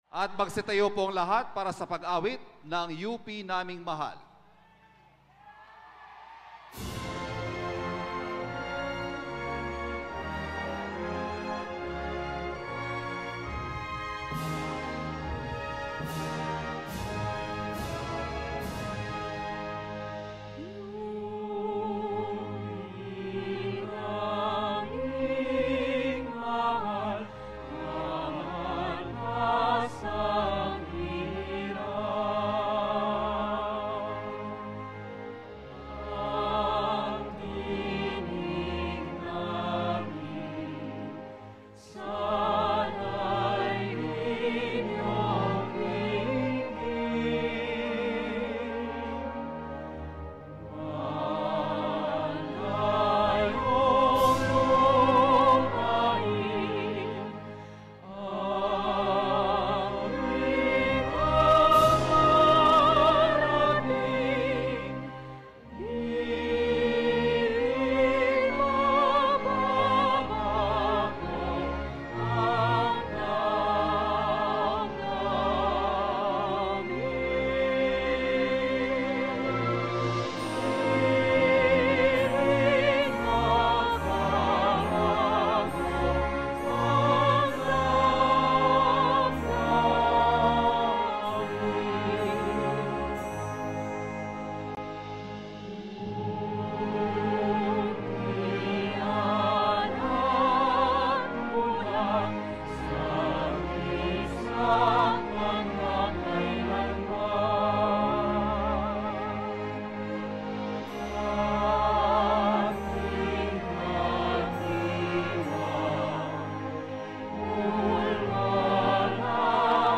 🌻🦅 The University of the Philippines Diliman culminates its 114th General Commencement Exercises with the singing of the University Hymn, “U.P. Naming Mahal.”